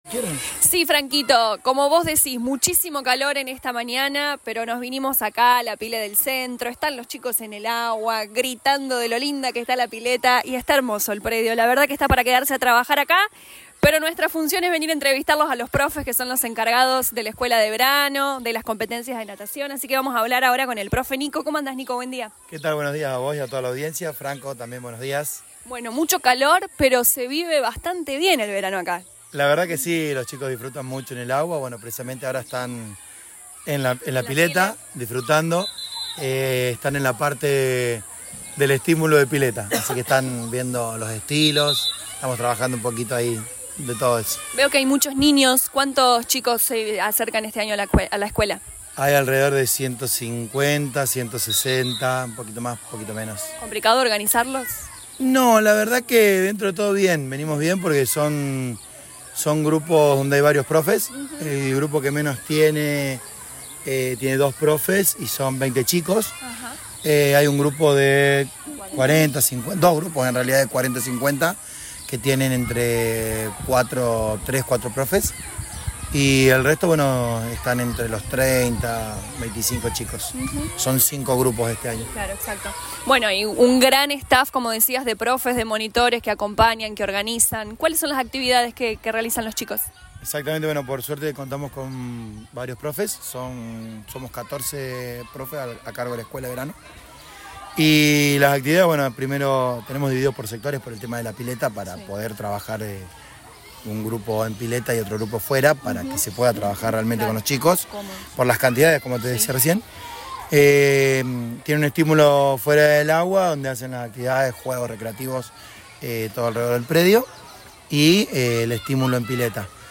LA RADIO 102.9 FM visitó el Complejo de Verano de Club Centro Social